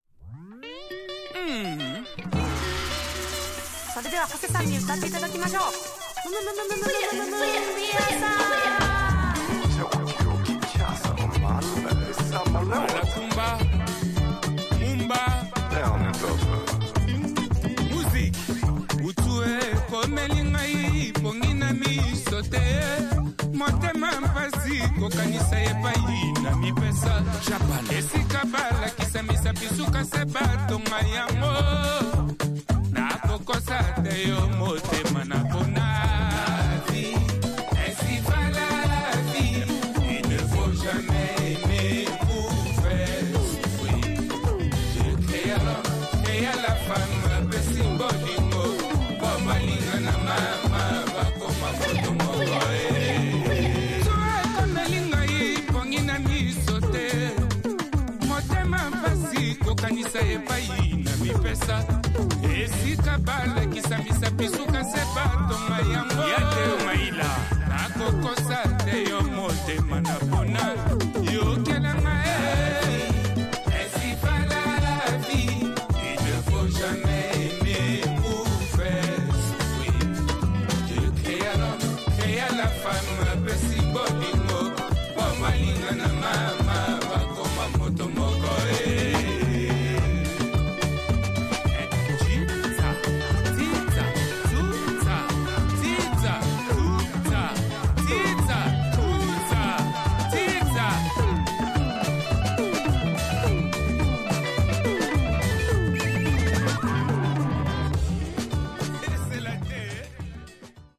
Tags: Cumbia , Champeta , Japan
Two superb dancefloor cumbia / champeta tunes.